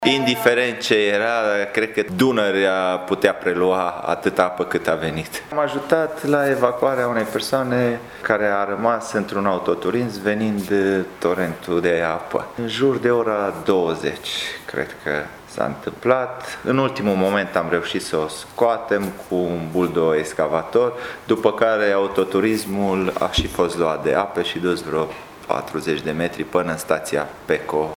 Primarul mun. Târnăveni, Sorin Megheșan spune că ploaia care a durat aproximativ o oră și jumătate a avut un debit de peste 150 de l/mp, formând torente care s-au revărsat în pârâul Botorca: